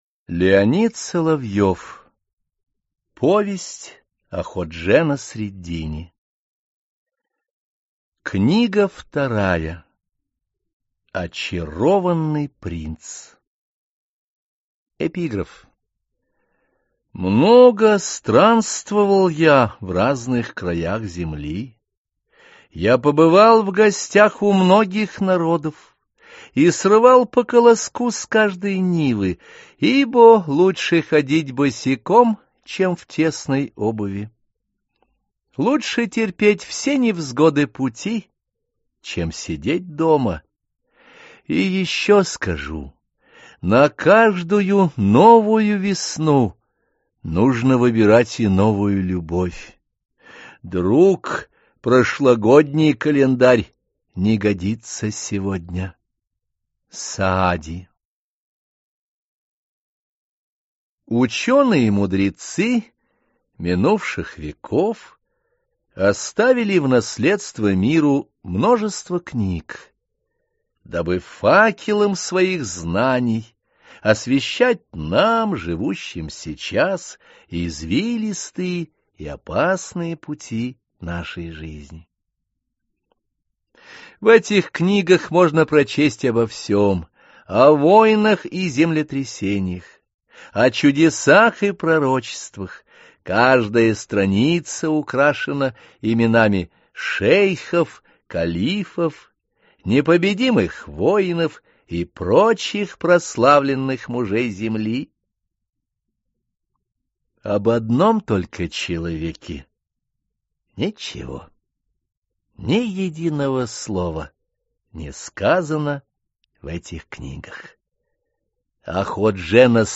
Аудиокнига Очарованный принц | Библиотека аудиокниг